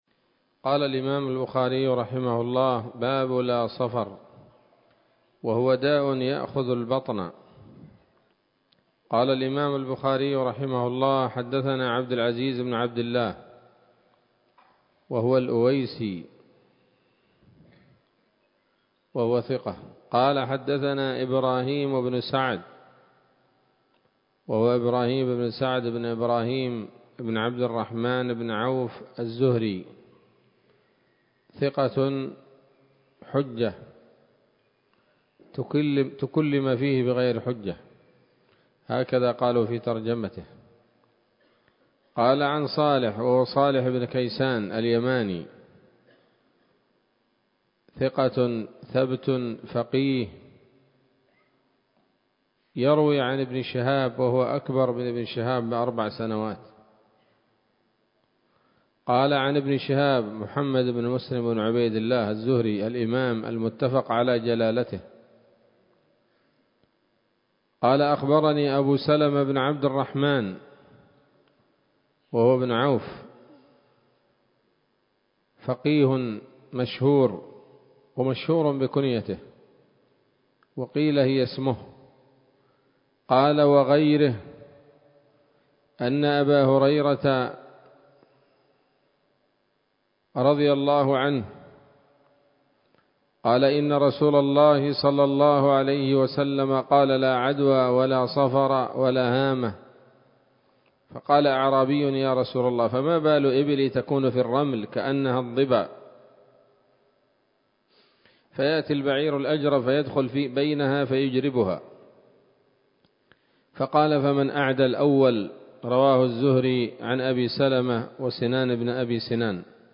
الدرس العشرون من كتاب الطب من صحيح الإمام البخاري